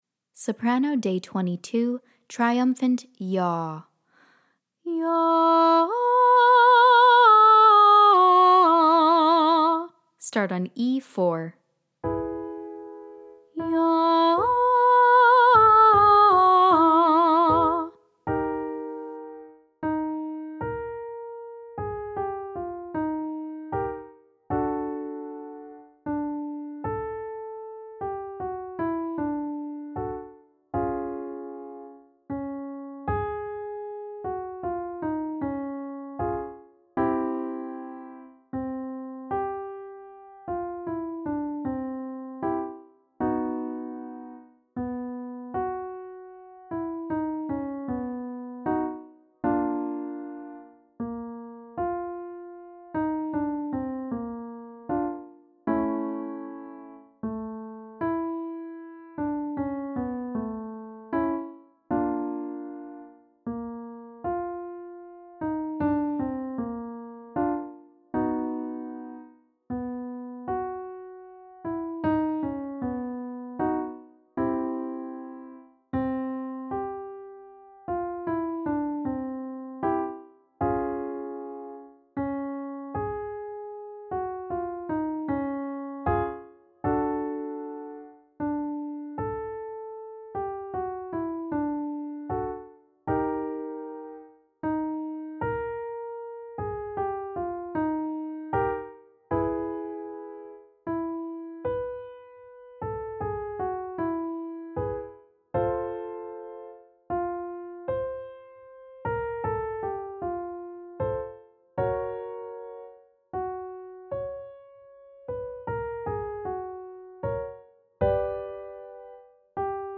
Day 22 - Soprano - Triumphant 'YAH' & Vibrato